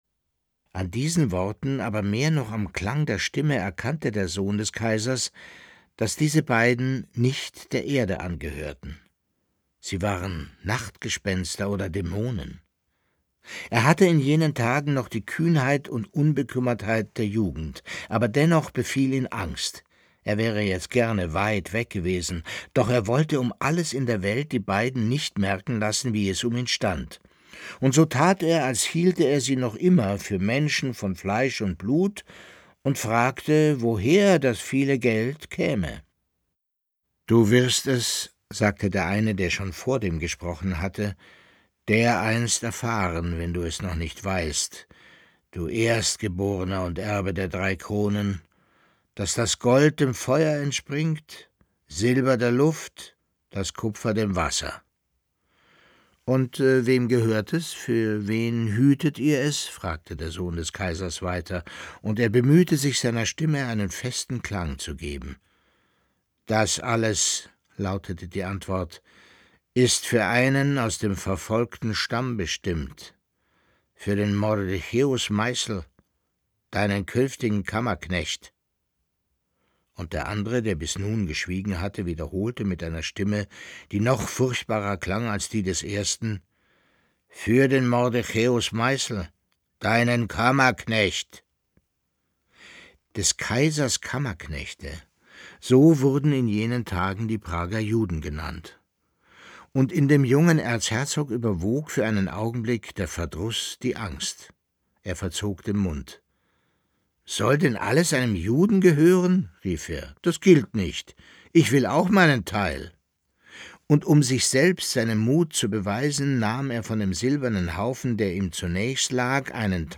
Leo Perutz: Nachts unter der steinernen Brücke (8/25) ~ Lesungen Podcast